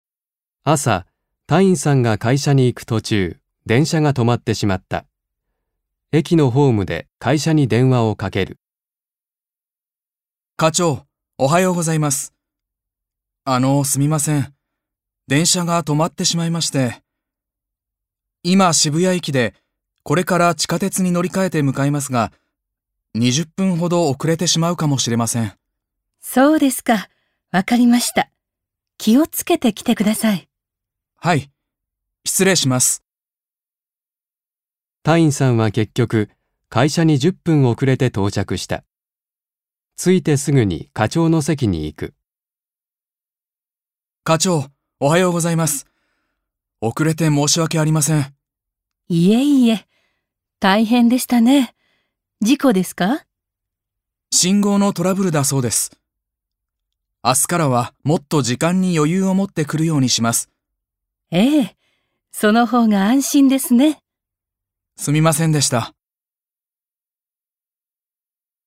1. 会話